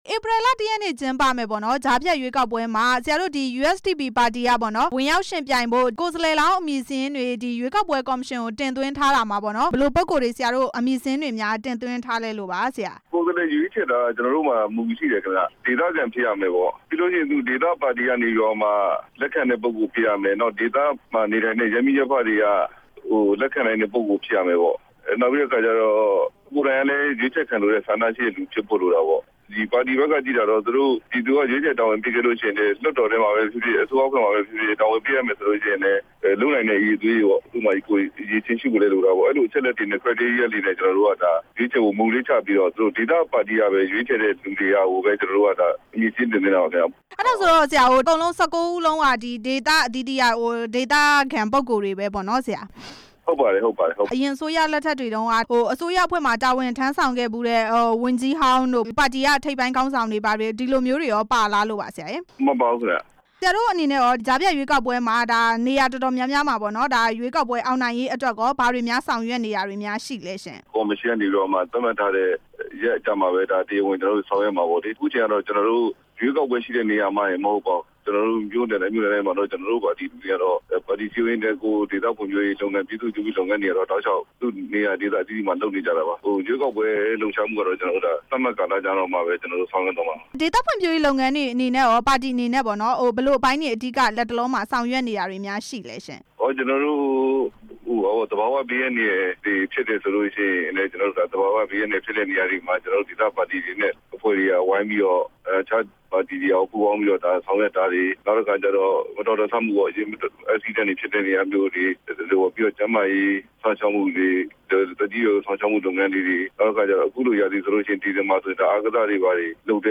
ကြံ့ခိုင်ဖွံ့ဖြိုးရေးပါတီ သတင်းထုတ်ပြန်ရေးအဖွဲ့ဝင်နဲ့ မေးမြန်းချက်